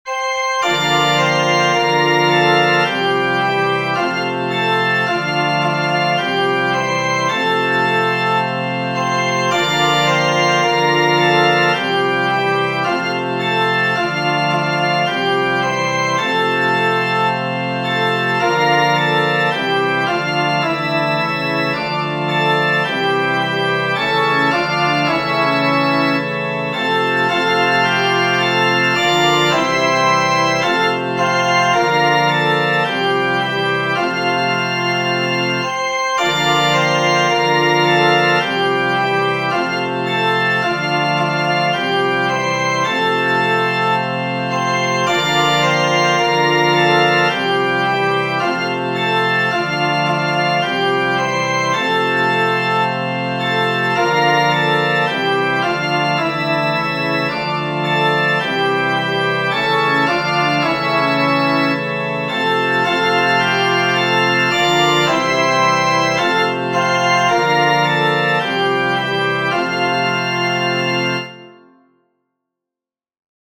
Genere: Religiose